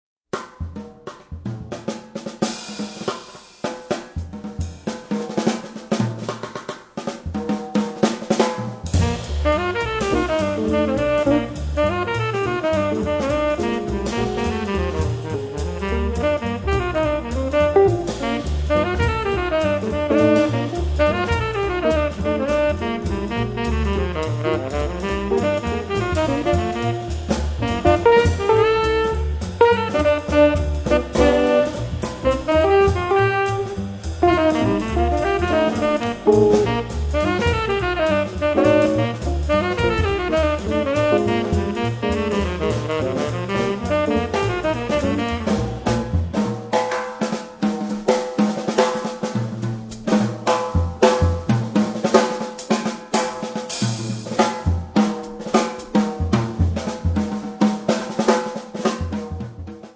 batteria
sax tenore
chitarre
contrabbasso